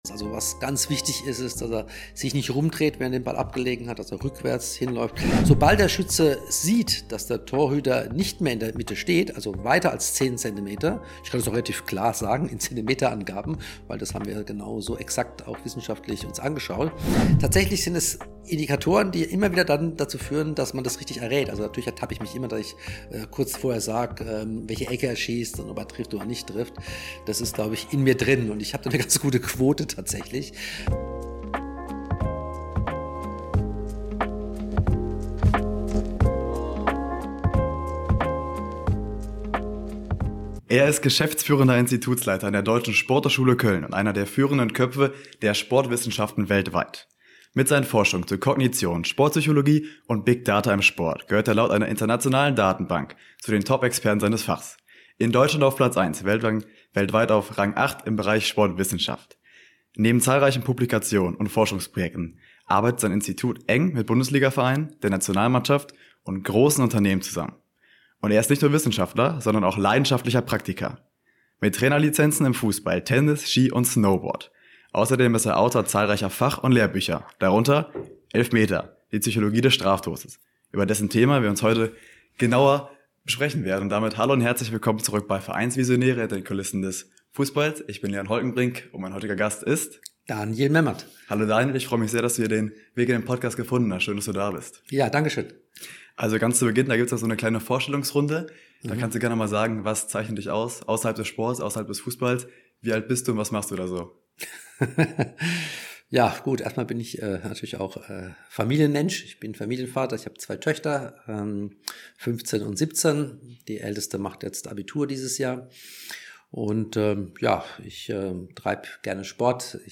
Wie sehr entscheidet der Kopf über Erfolg oder Misserfolg? Und welche Rolle spielen Routinen, Datenanalysen und Torwart-Tricks? Ein Gespräch über Wissenschaft, Drucksituationen und die Faszination vom Punkt.